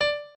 pianoadrib1_64.ogg